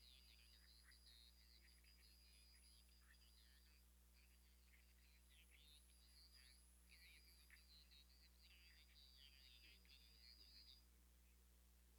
This WAV file was recorded using the I and Q streams for the R and L channels.
• The WAV file was captured at 48 kHz so change samp_rate to 48 kHz
There are two SSB voice signals in this file, both are upper sideband (USB), whereas the first data file was lower sideband (LSB).
One of the center frequencies in this file is -3500 Hz.